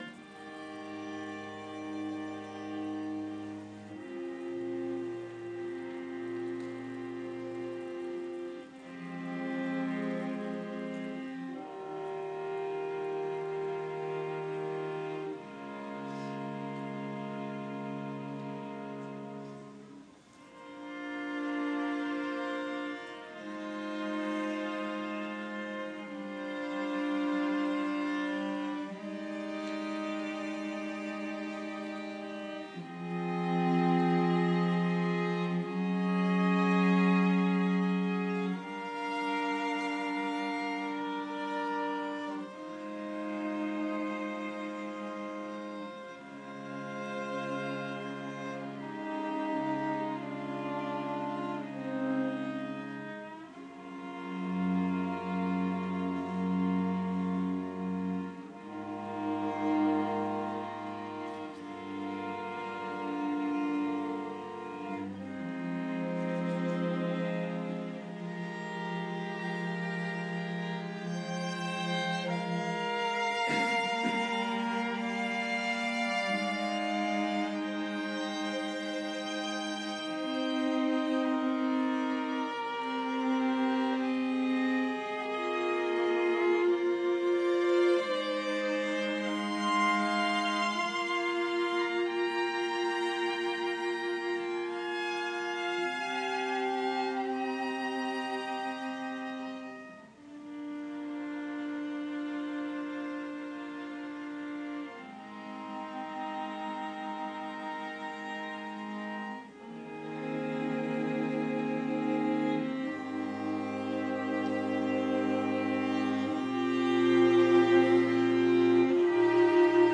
String Quartet snippet live